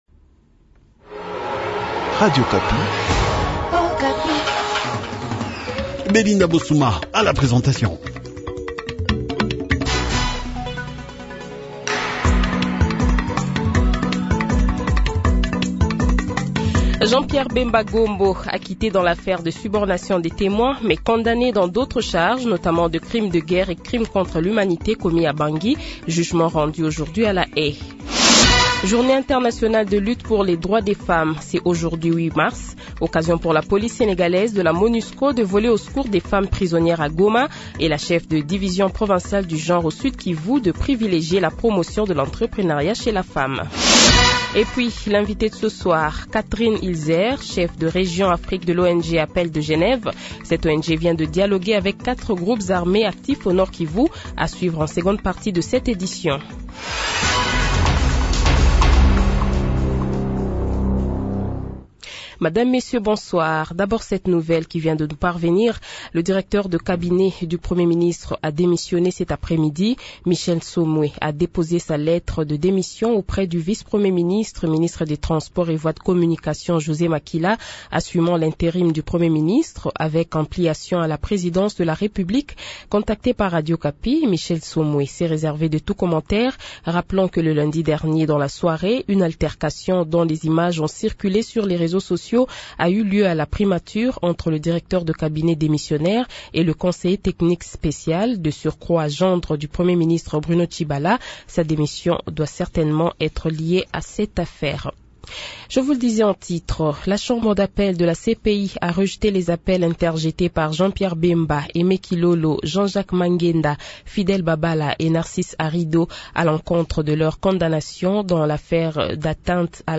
Journal Français Soir